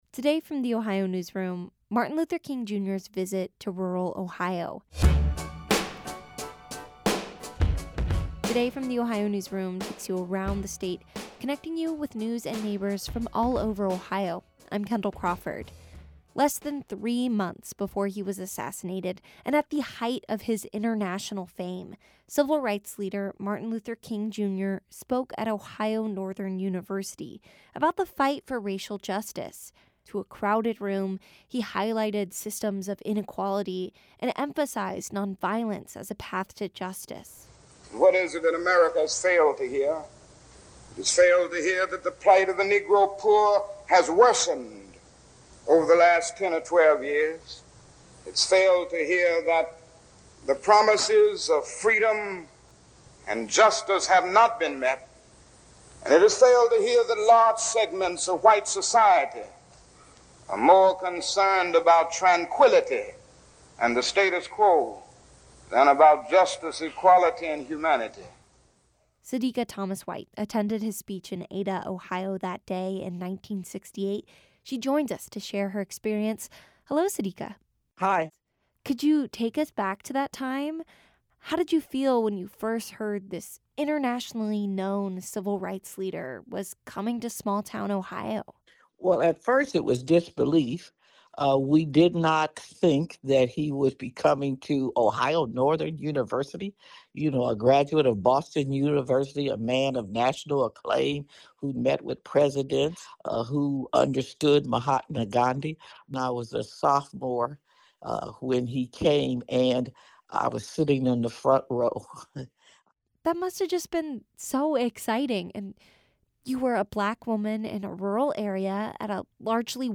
To a crowded room, he highlighted systems of inequality and emphasized nonviolence as a path to justice.
Civil rights leader Martin Luther King, Jr. speaks at Ohio Northern University in 1968.
This interview has been lightly edited for brevity and clarity.